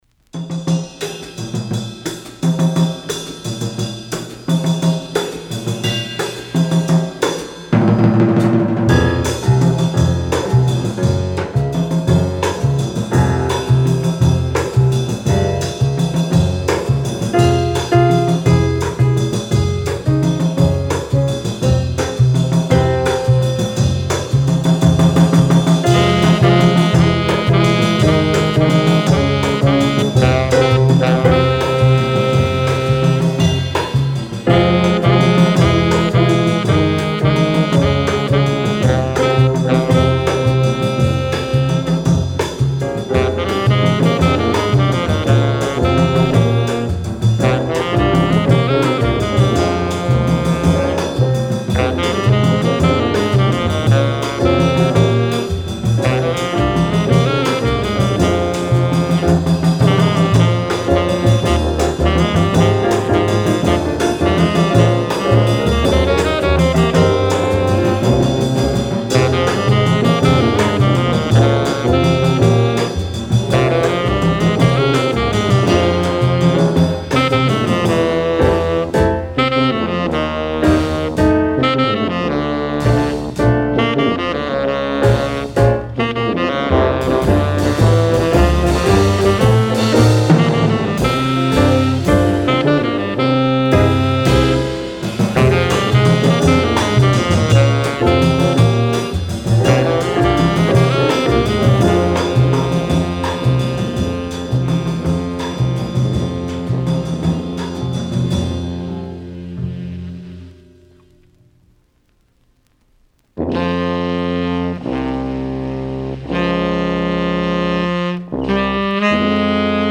Original mono pressing ca. 1958